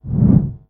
Звуки взмахов
Глуховатый звук